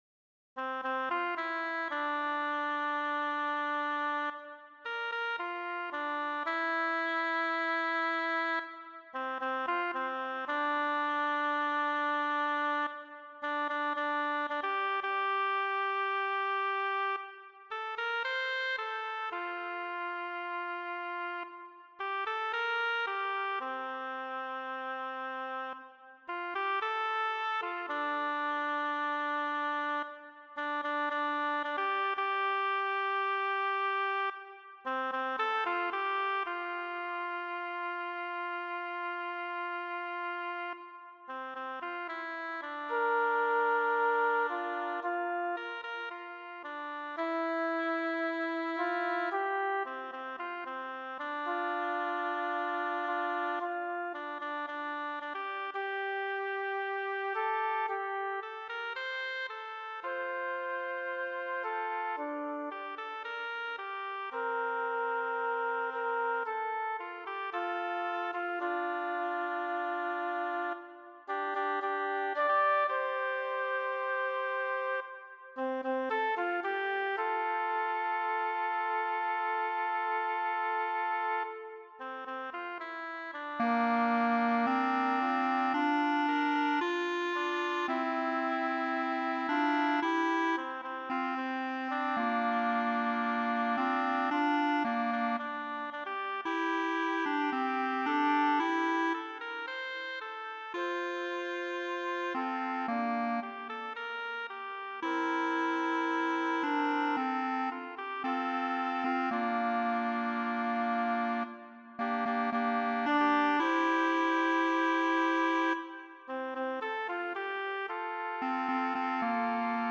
Break-of-Day-3part-PLAYBACKa.mp3